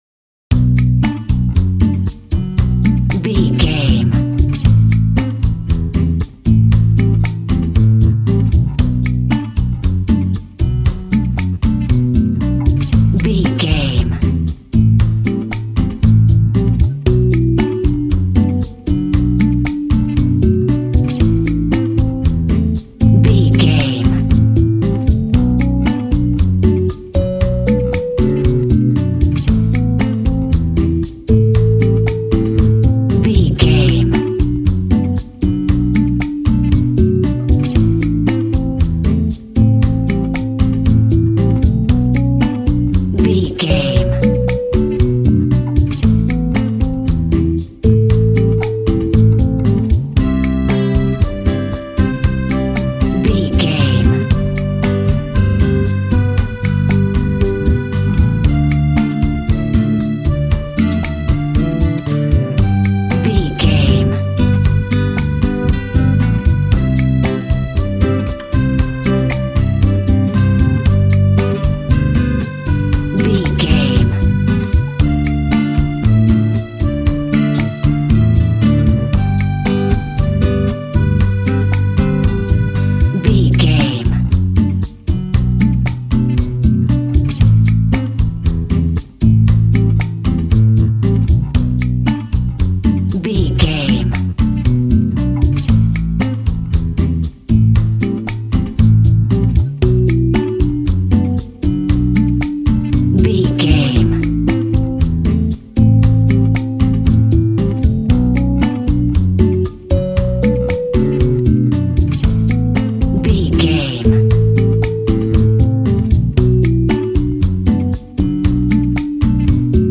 Ionian/Major
funky
energetic
romantic
percussion
electric guitar
acoustic guitar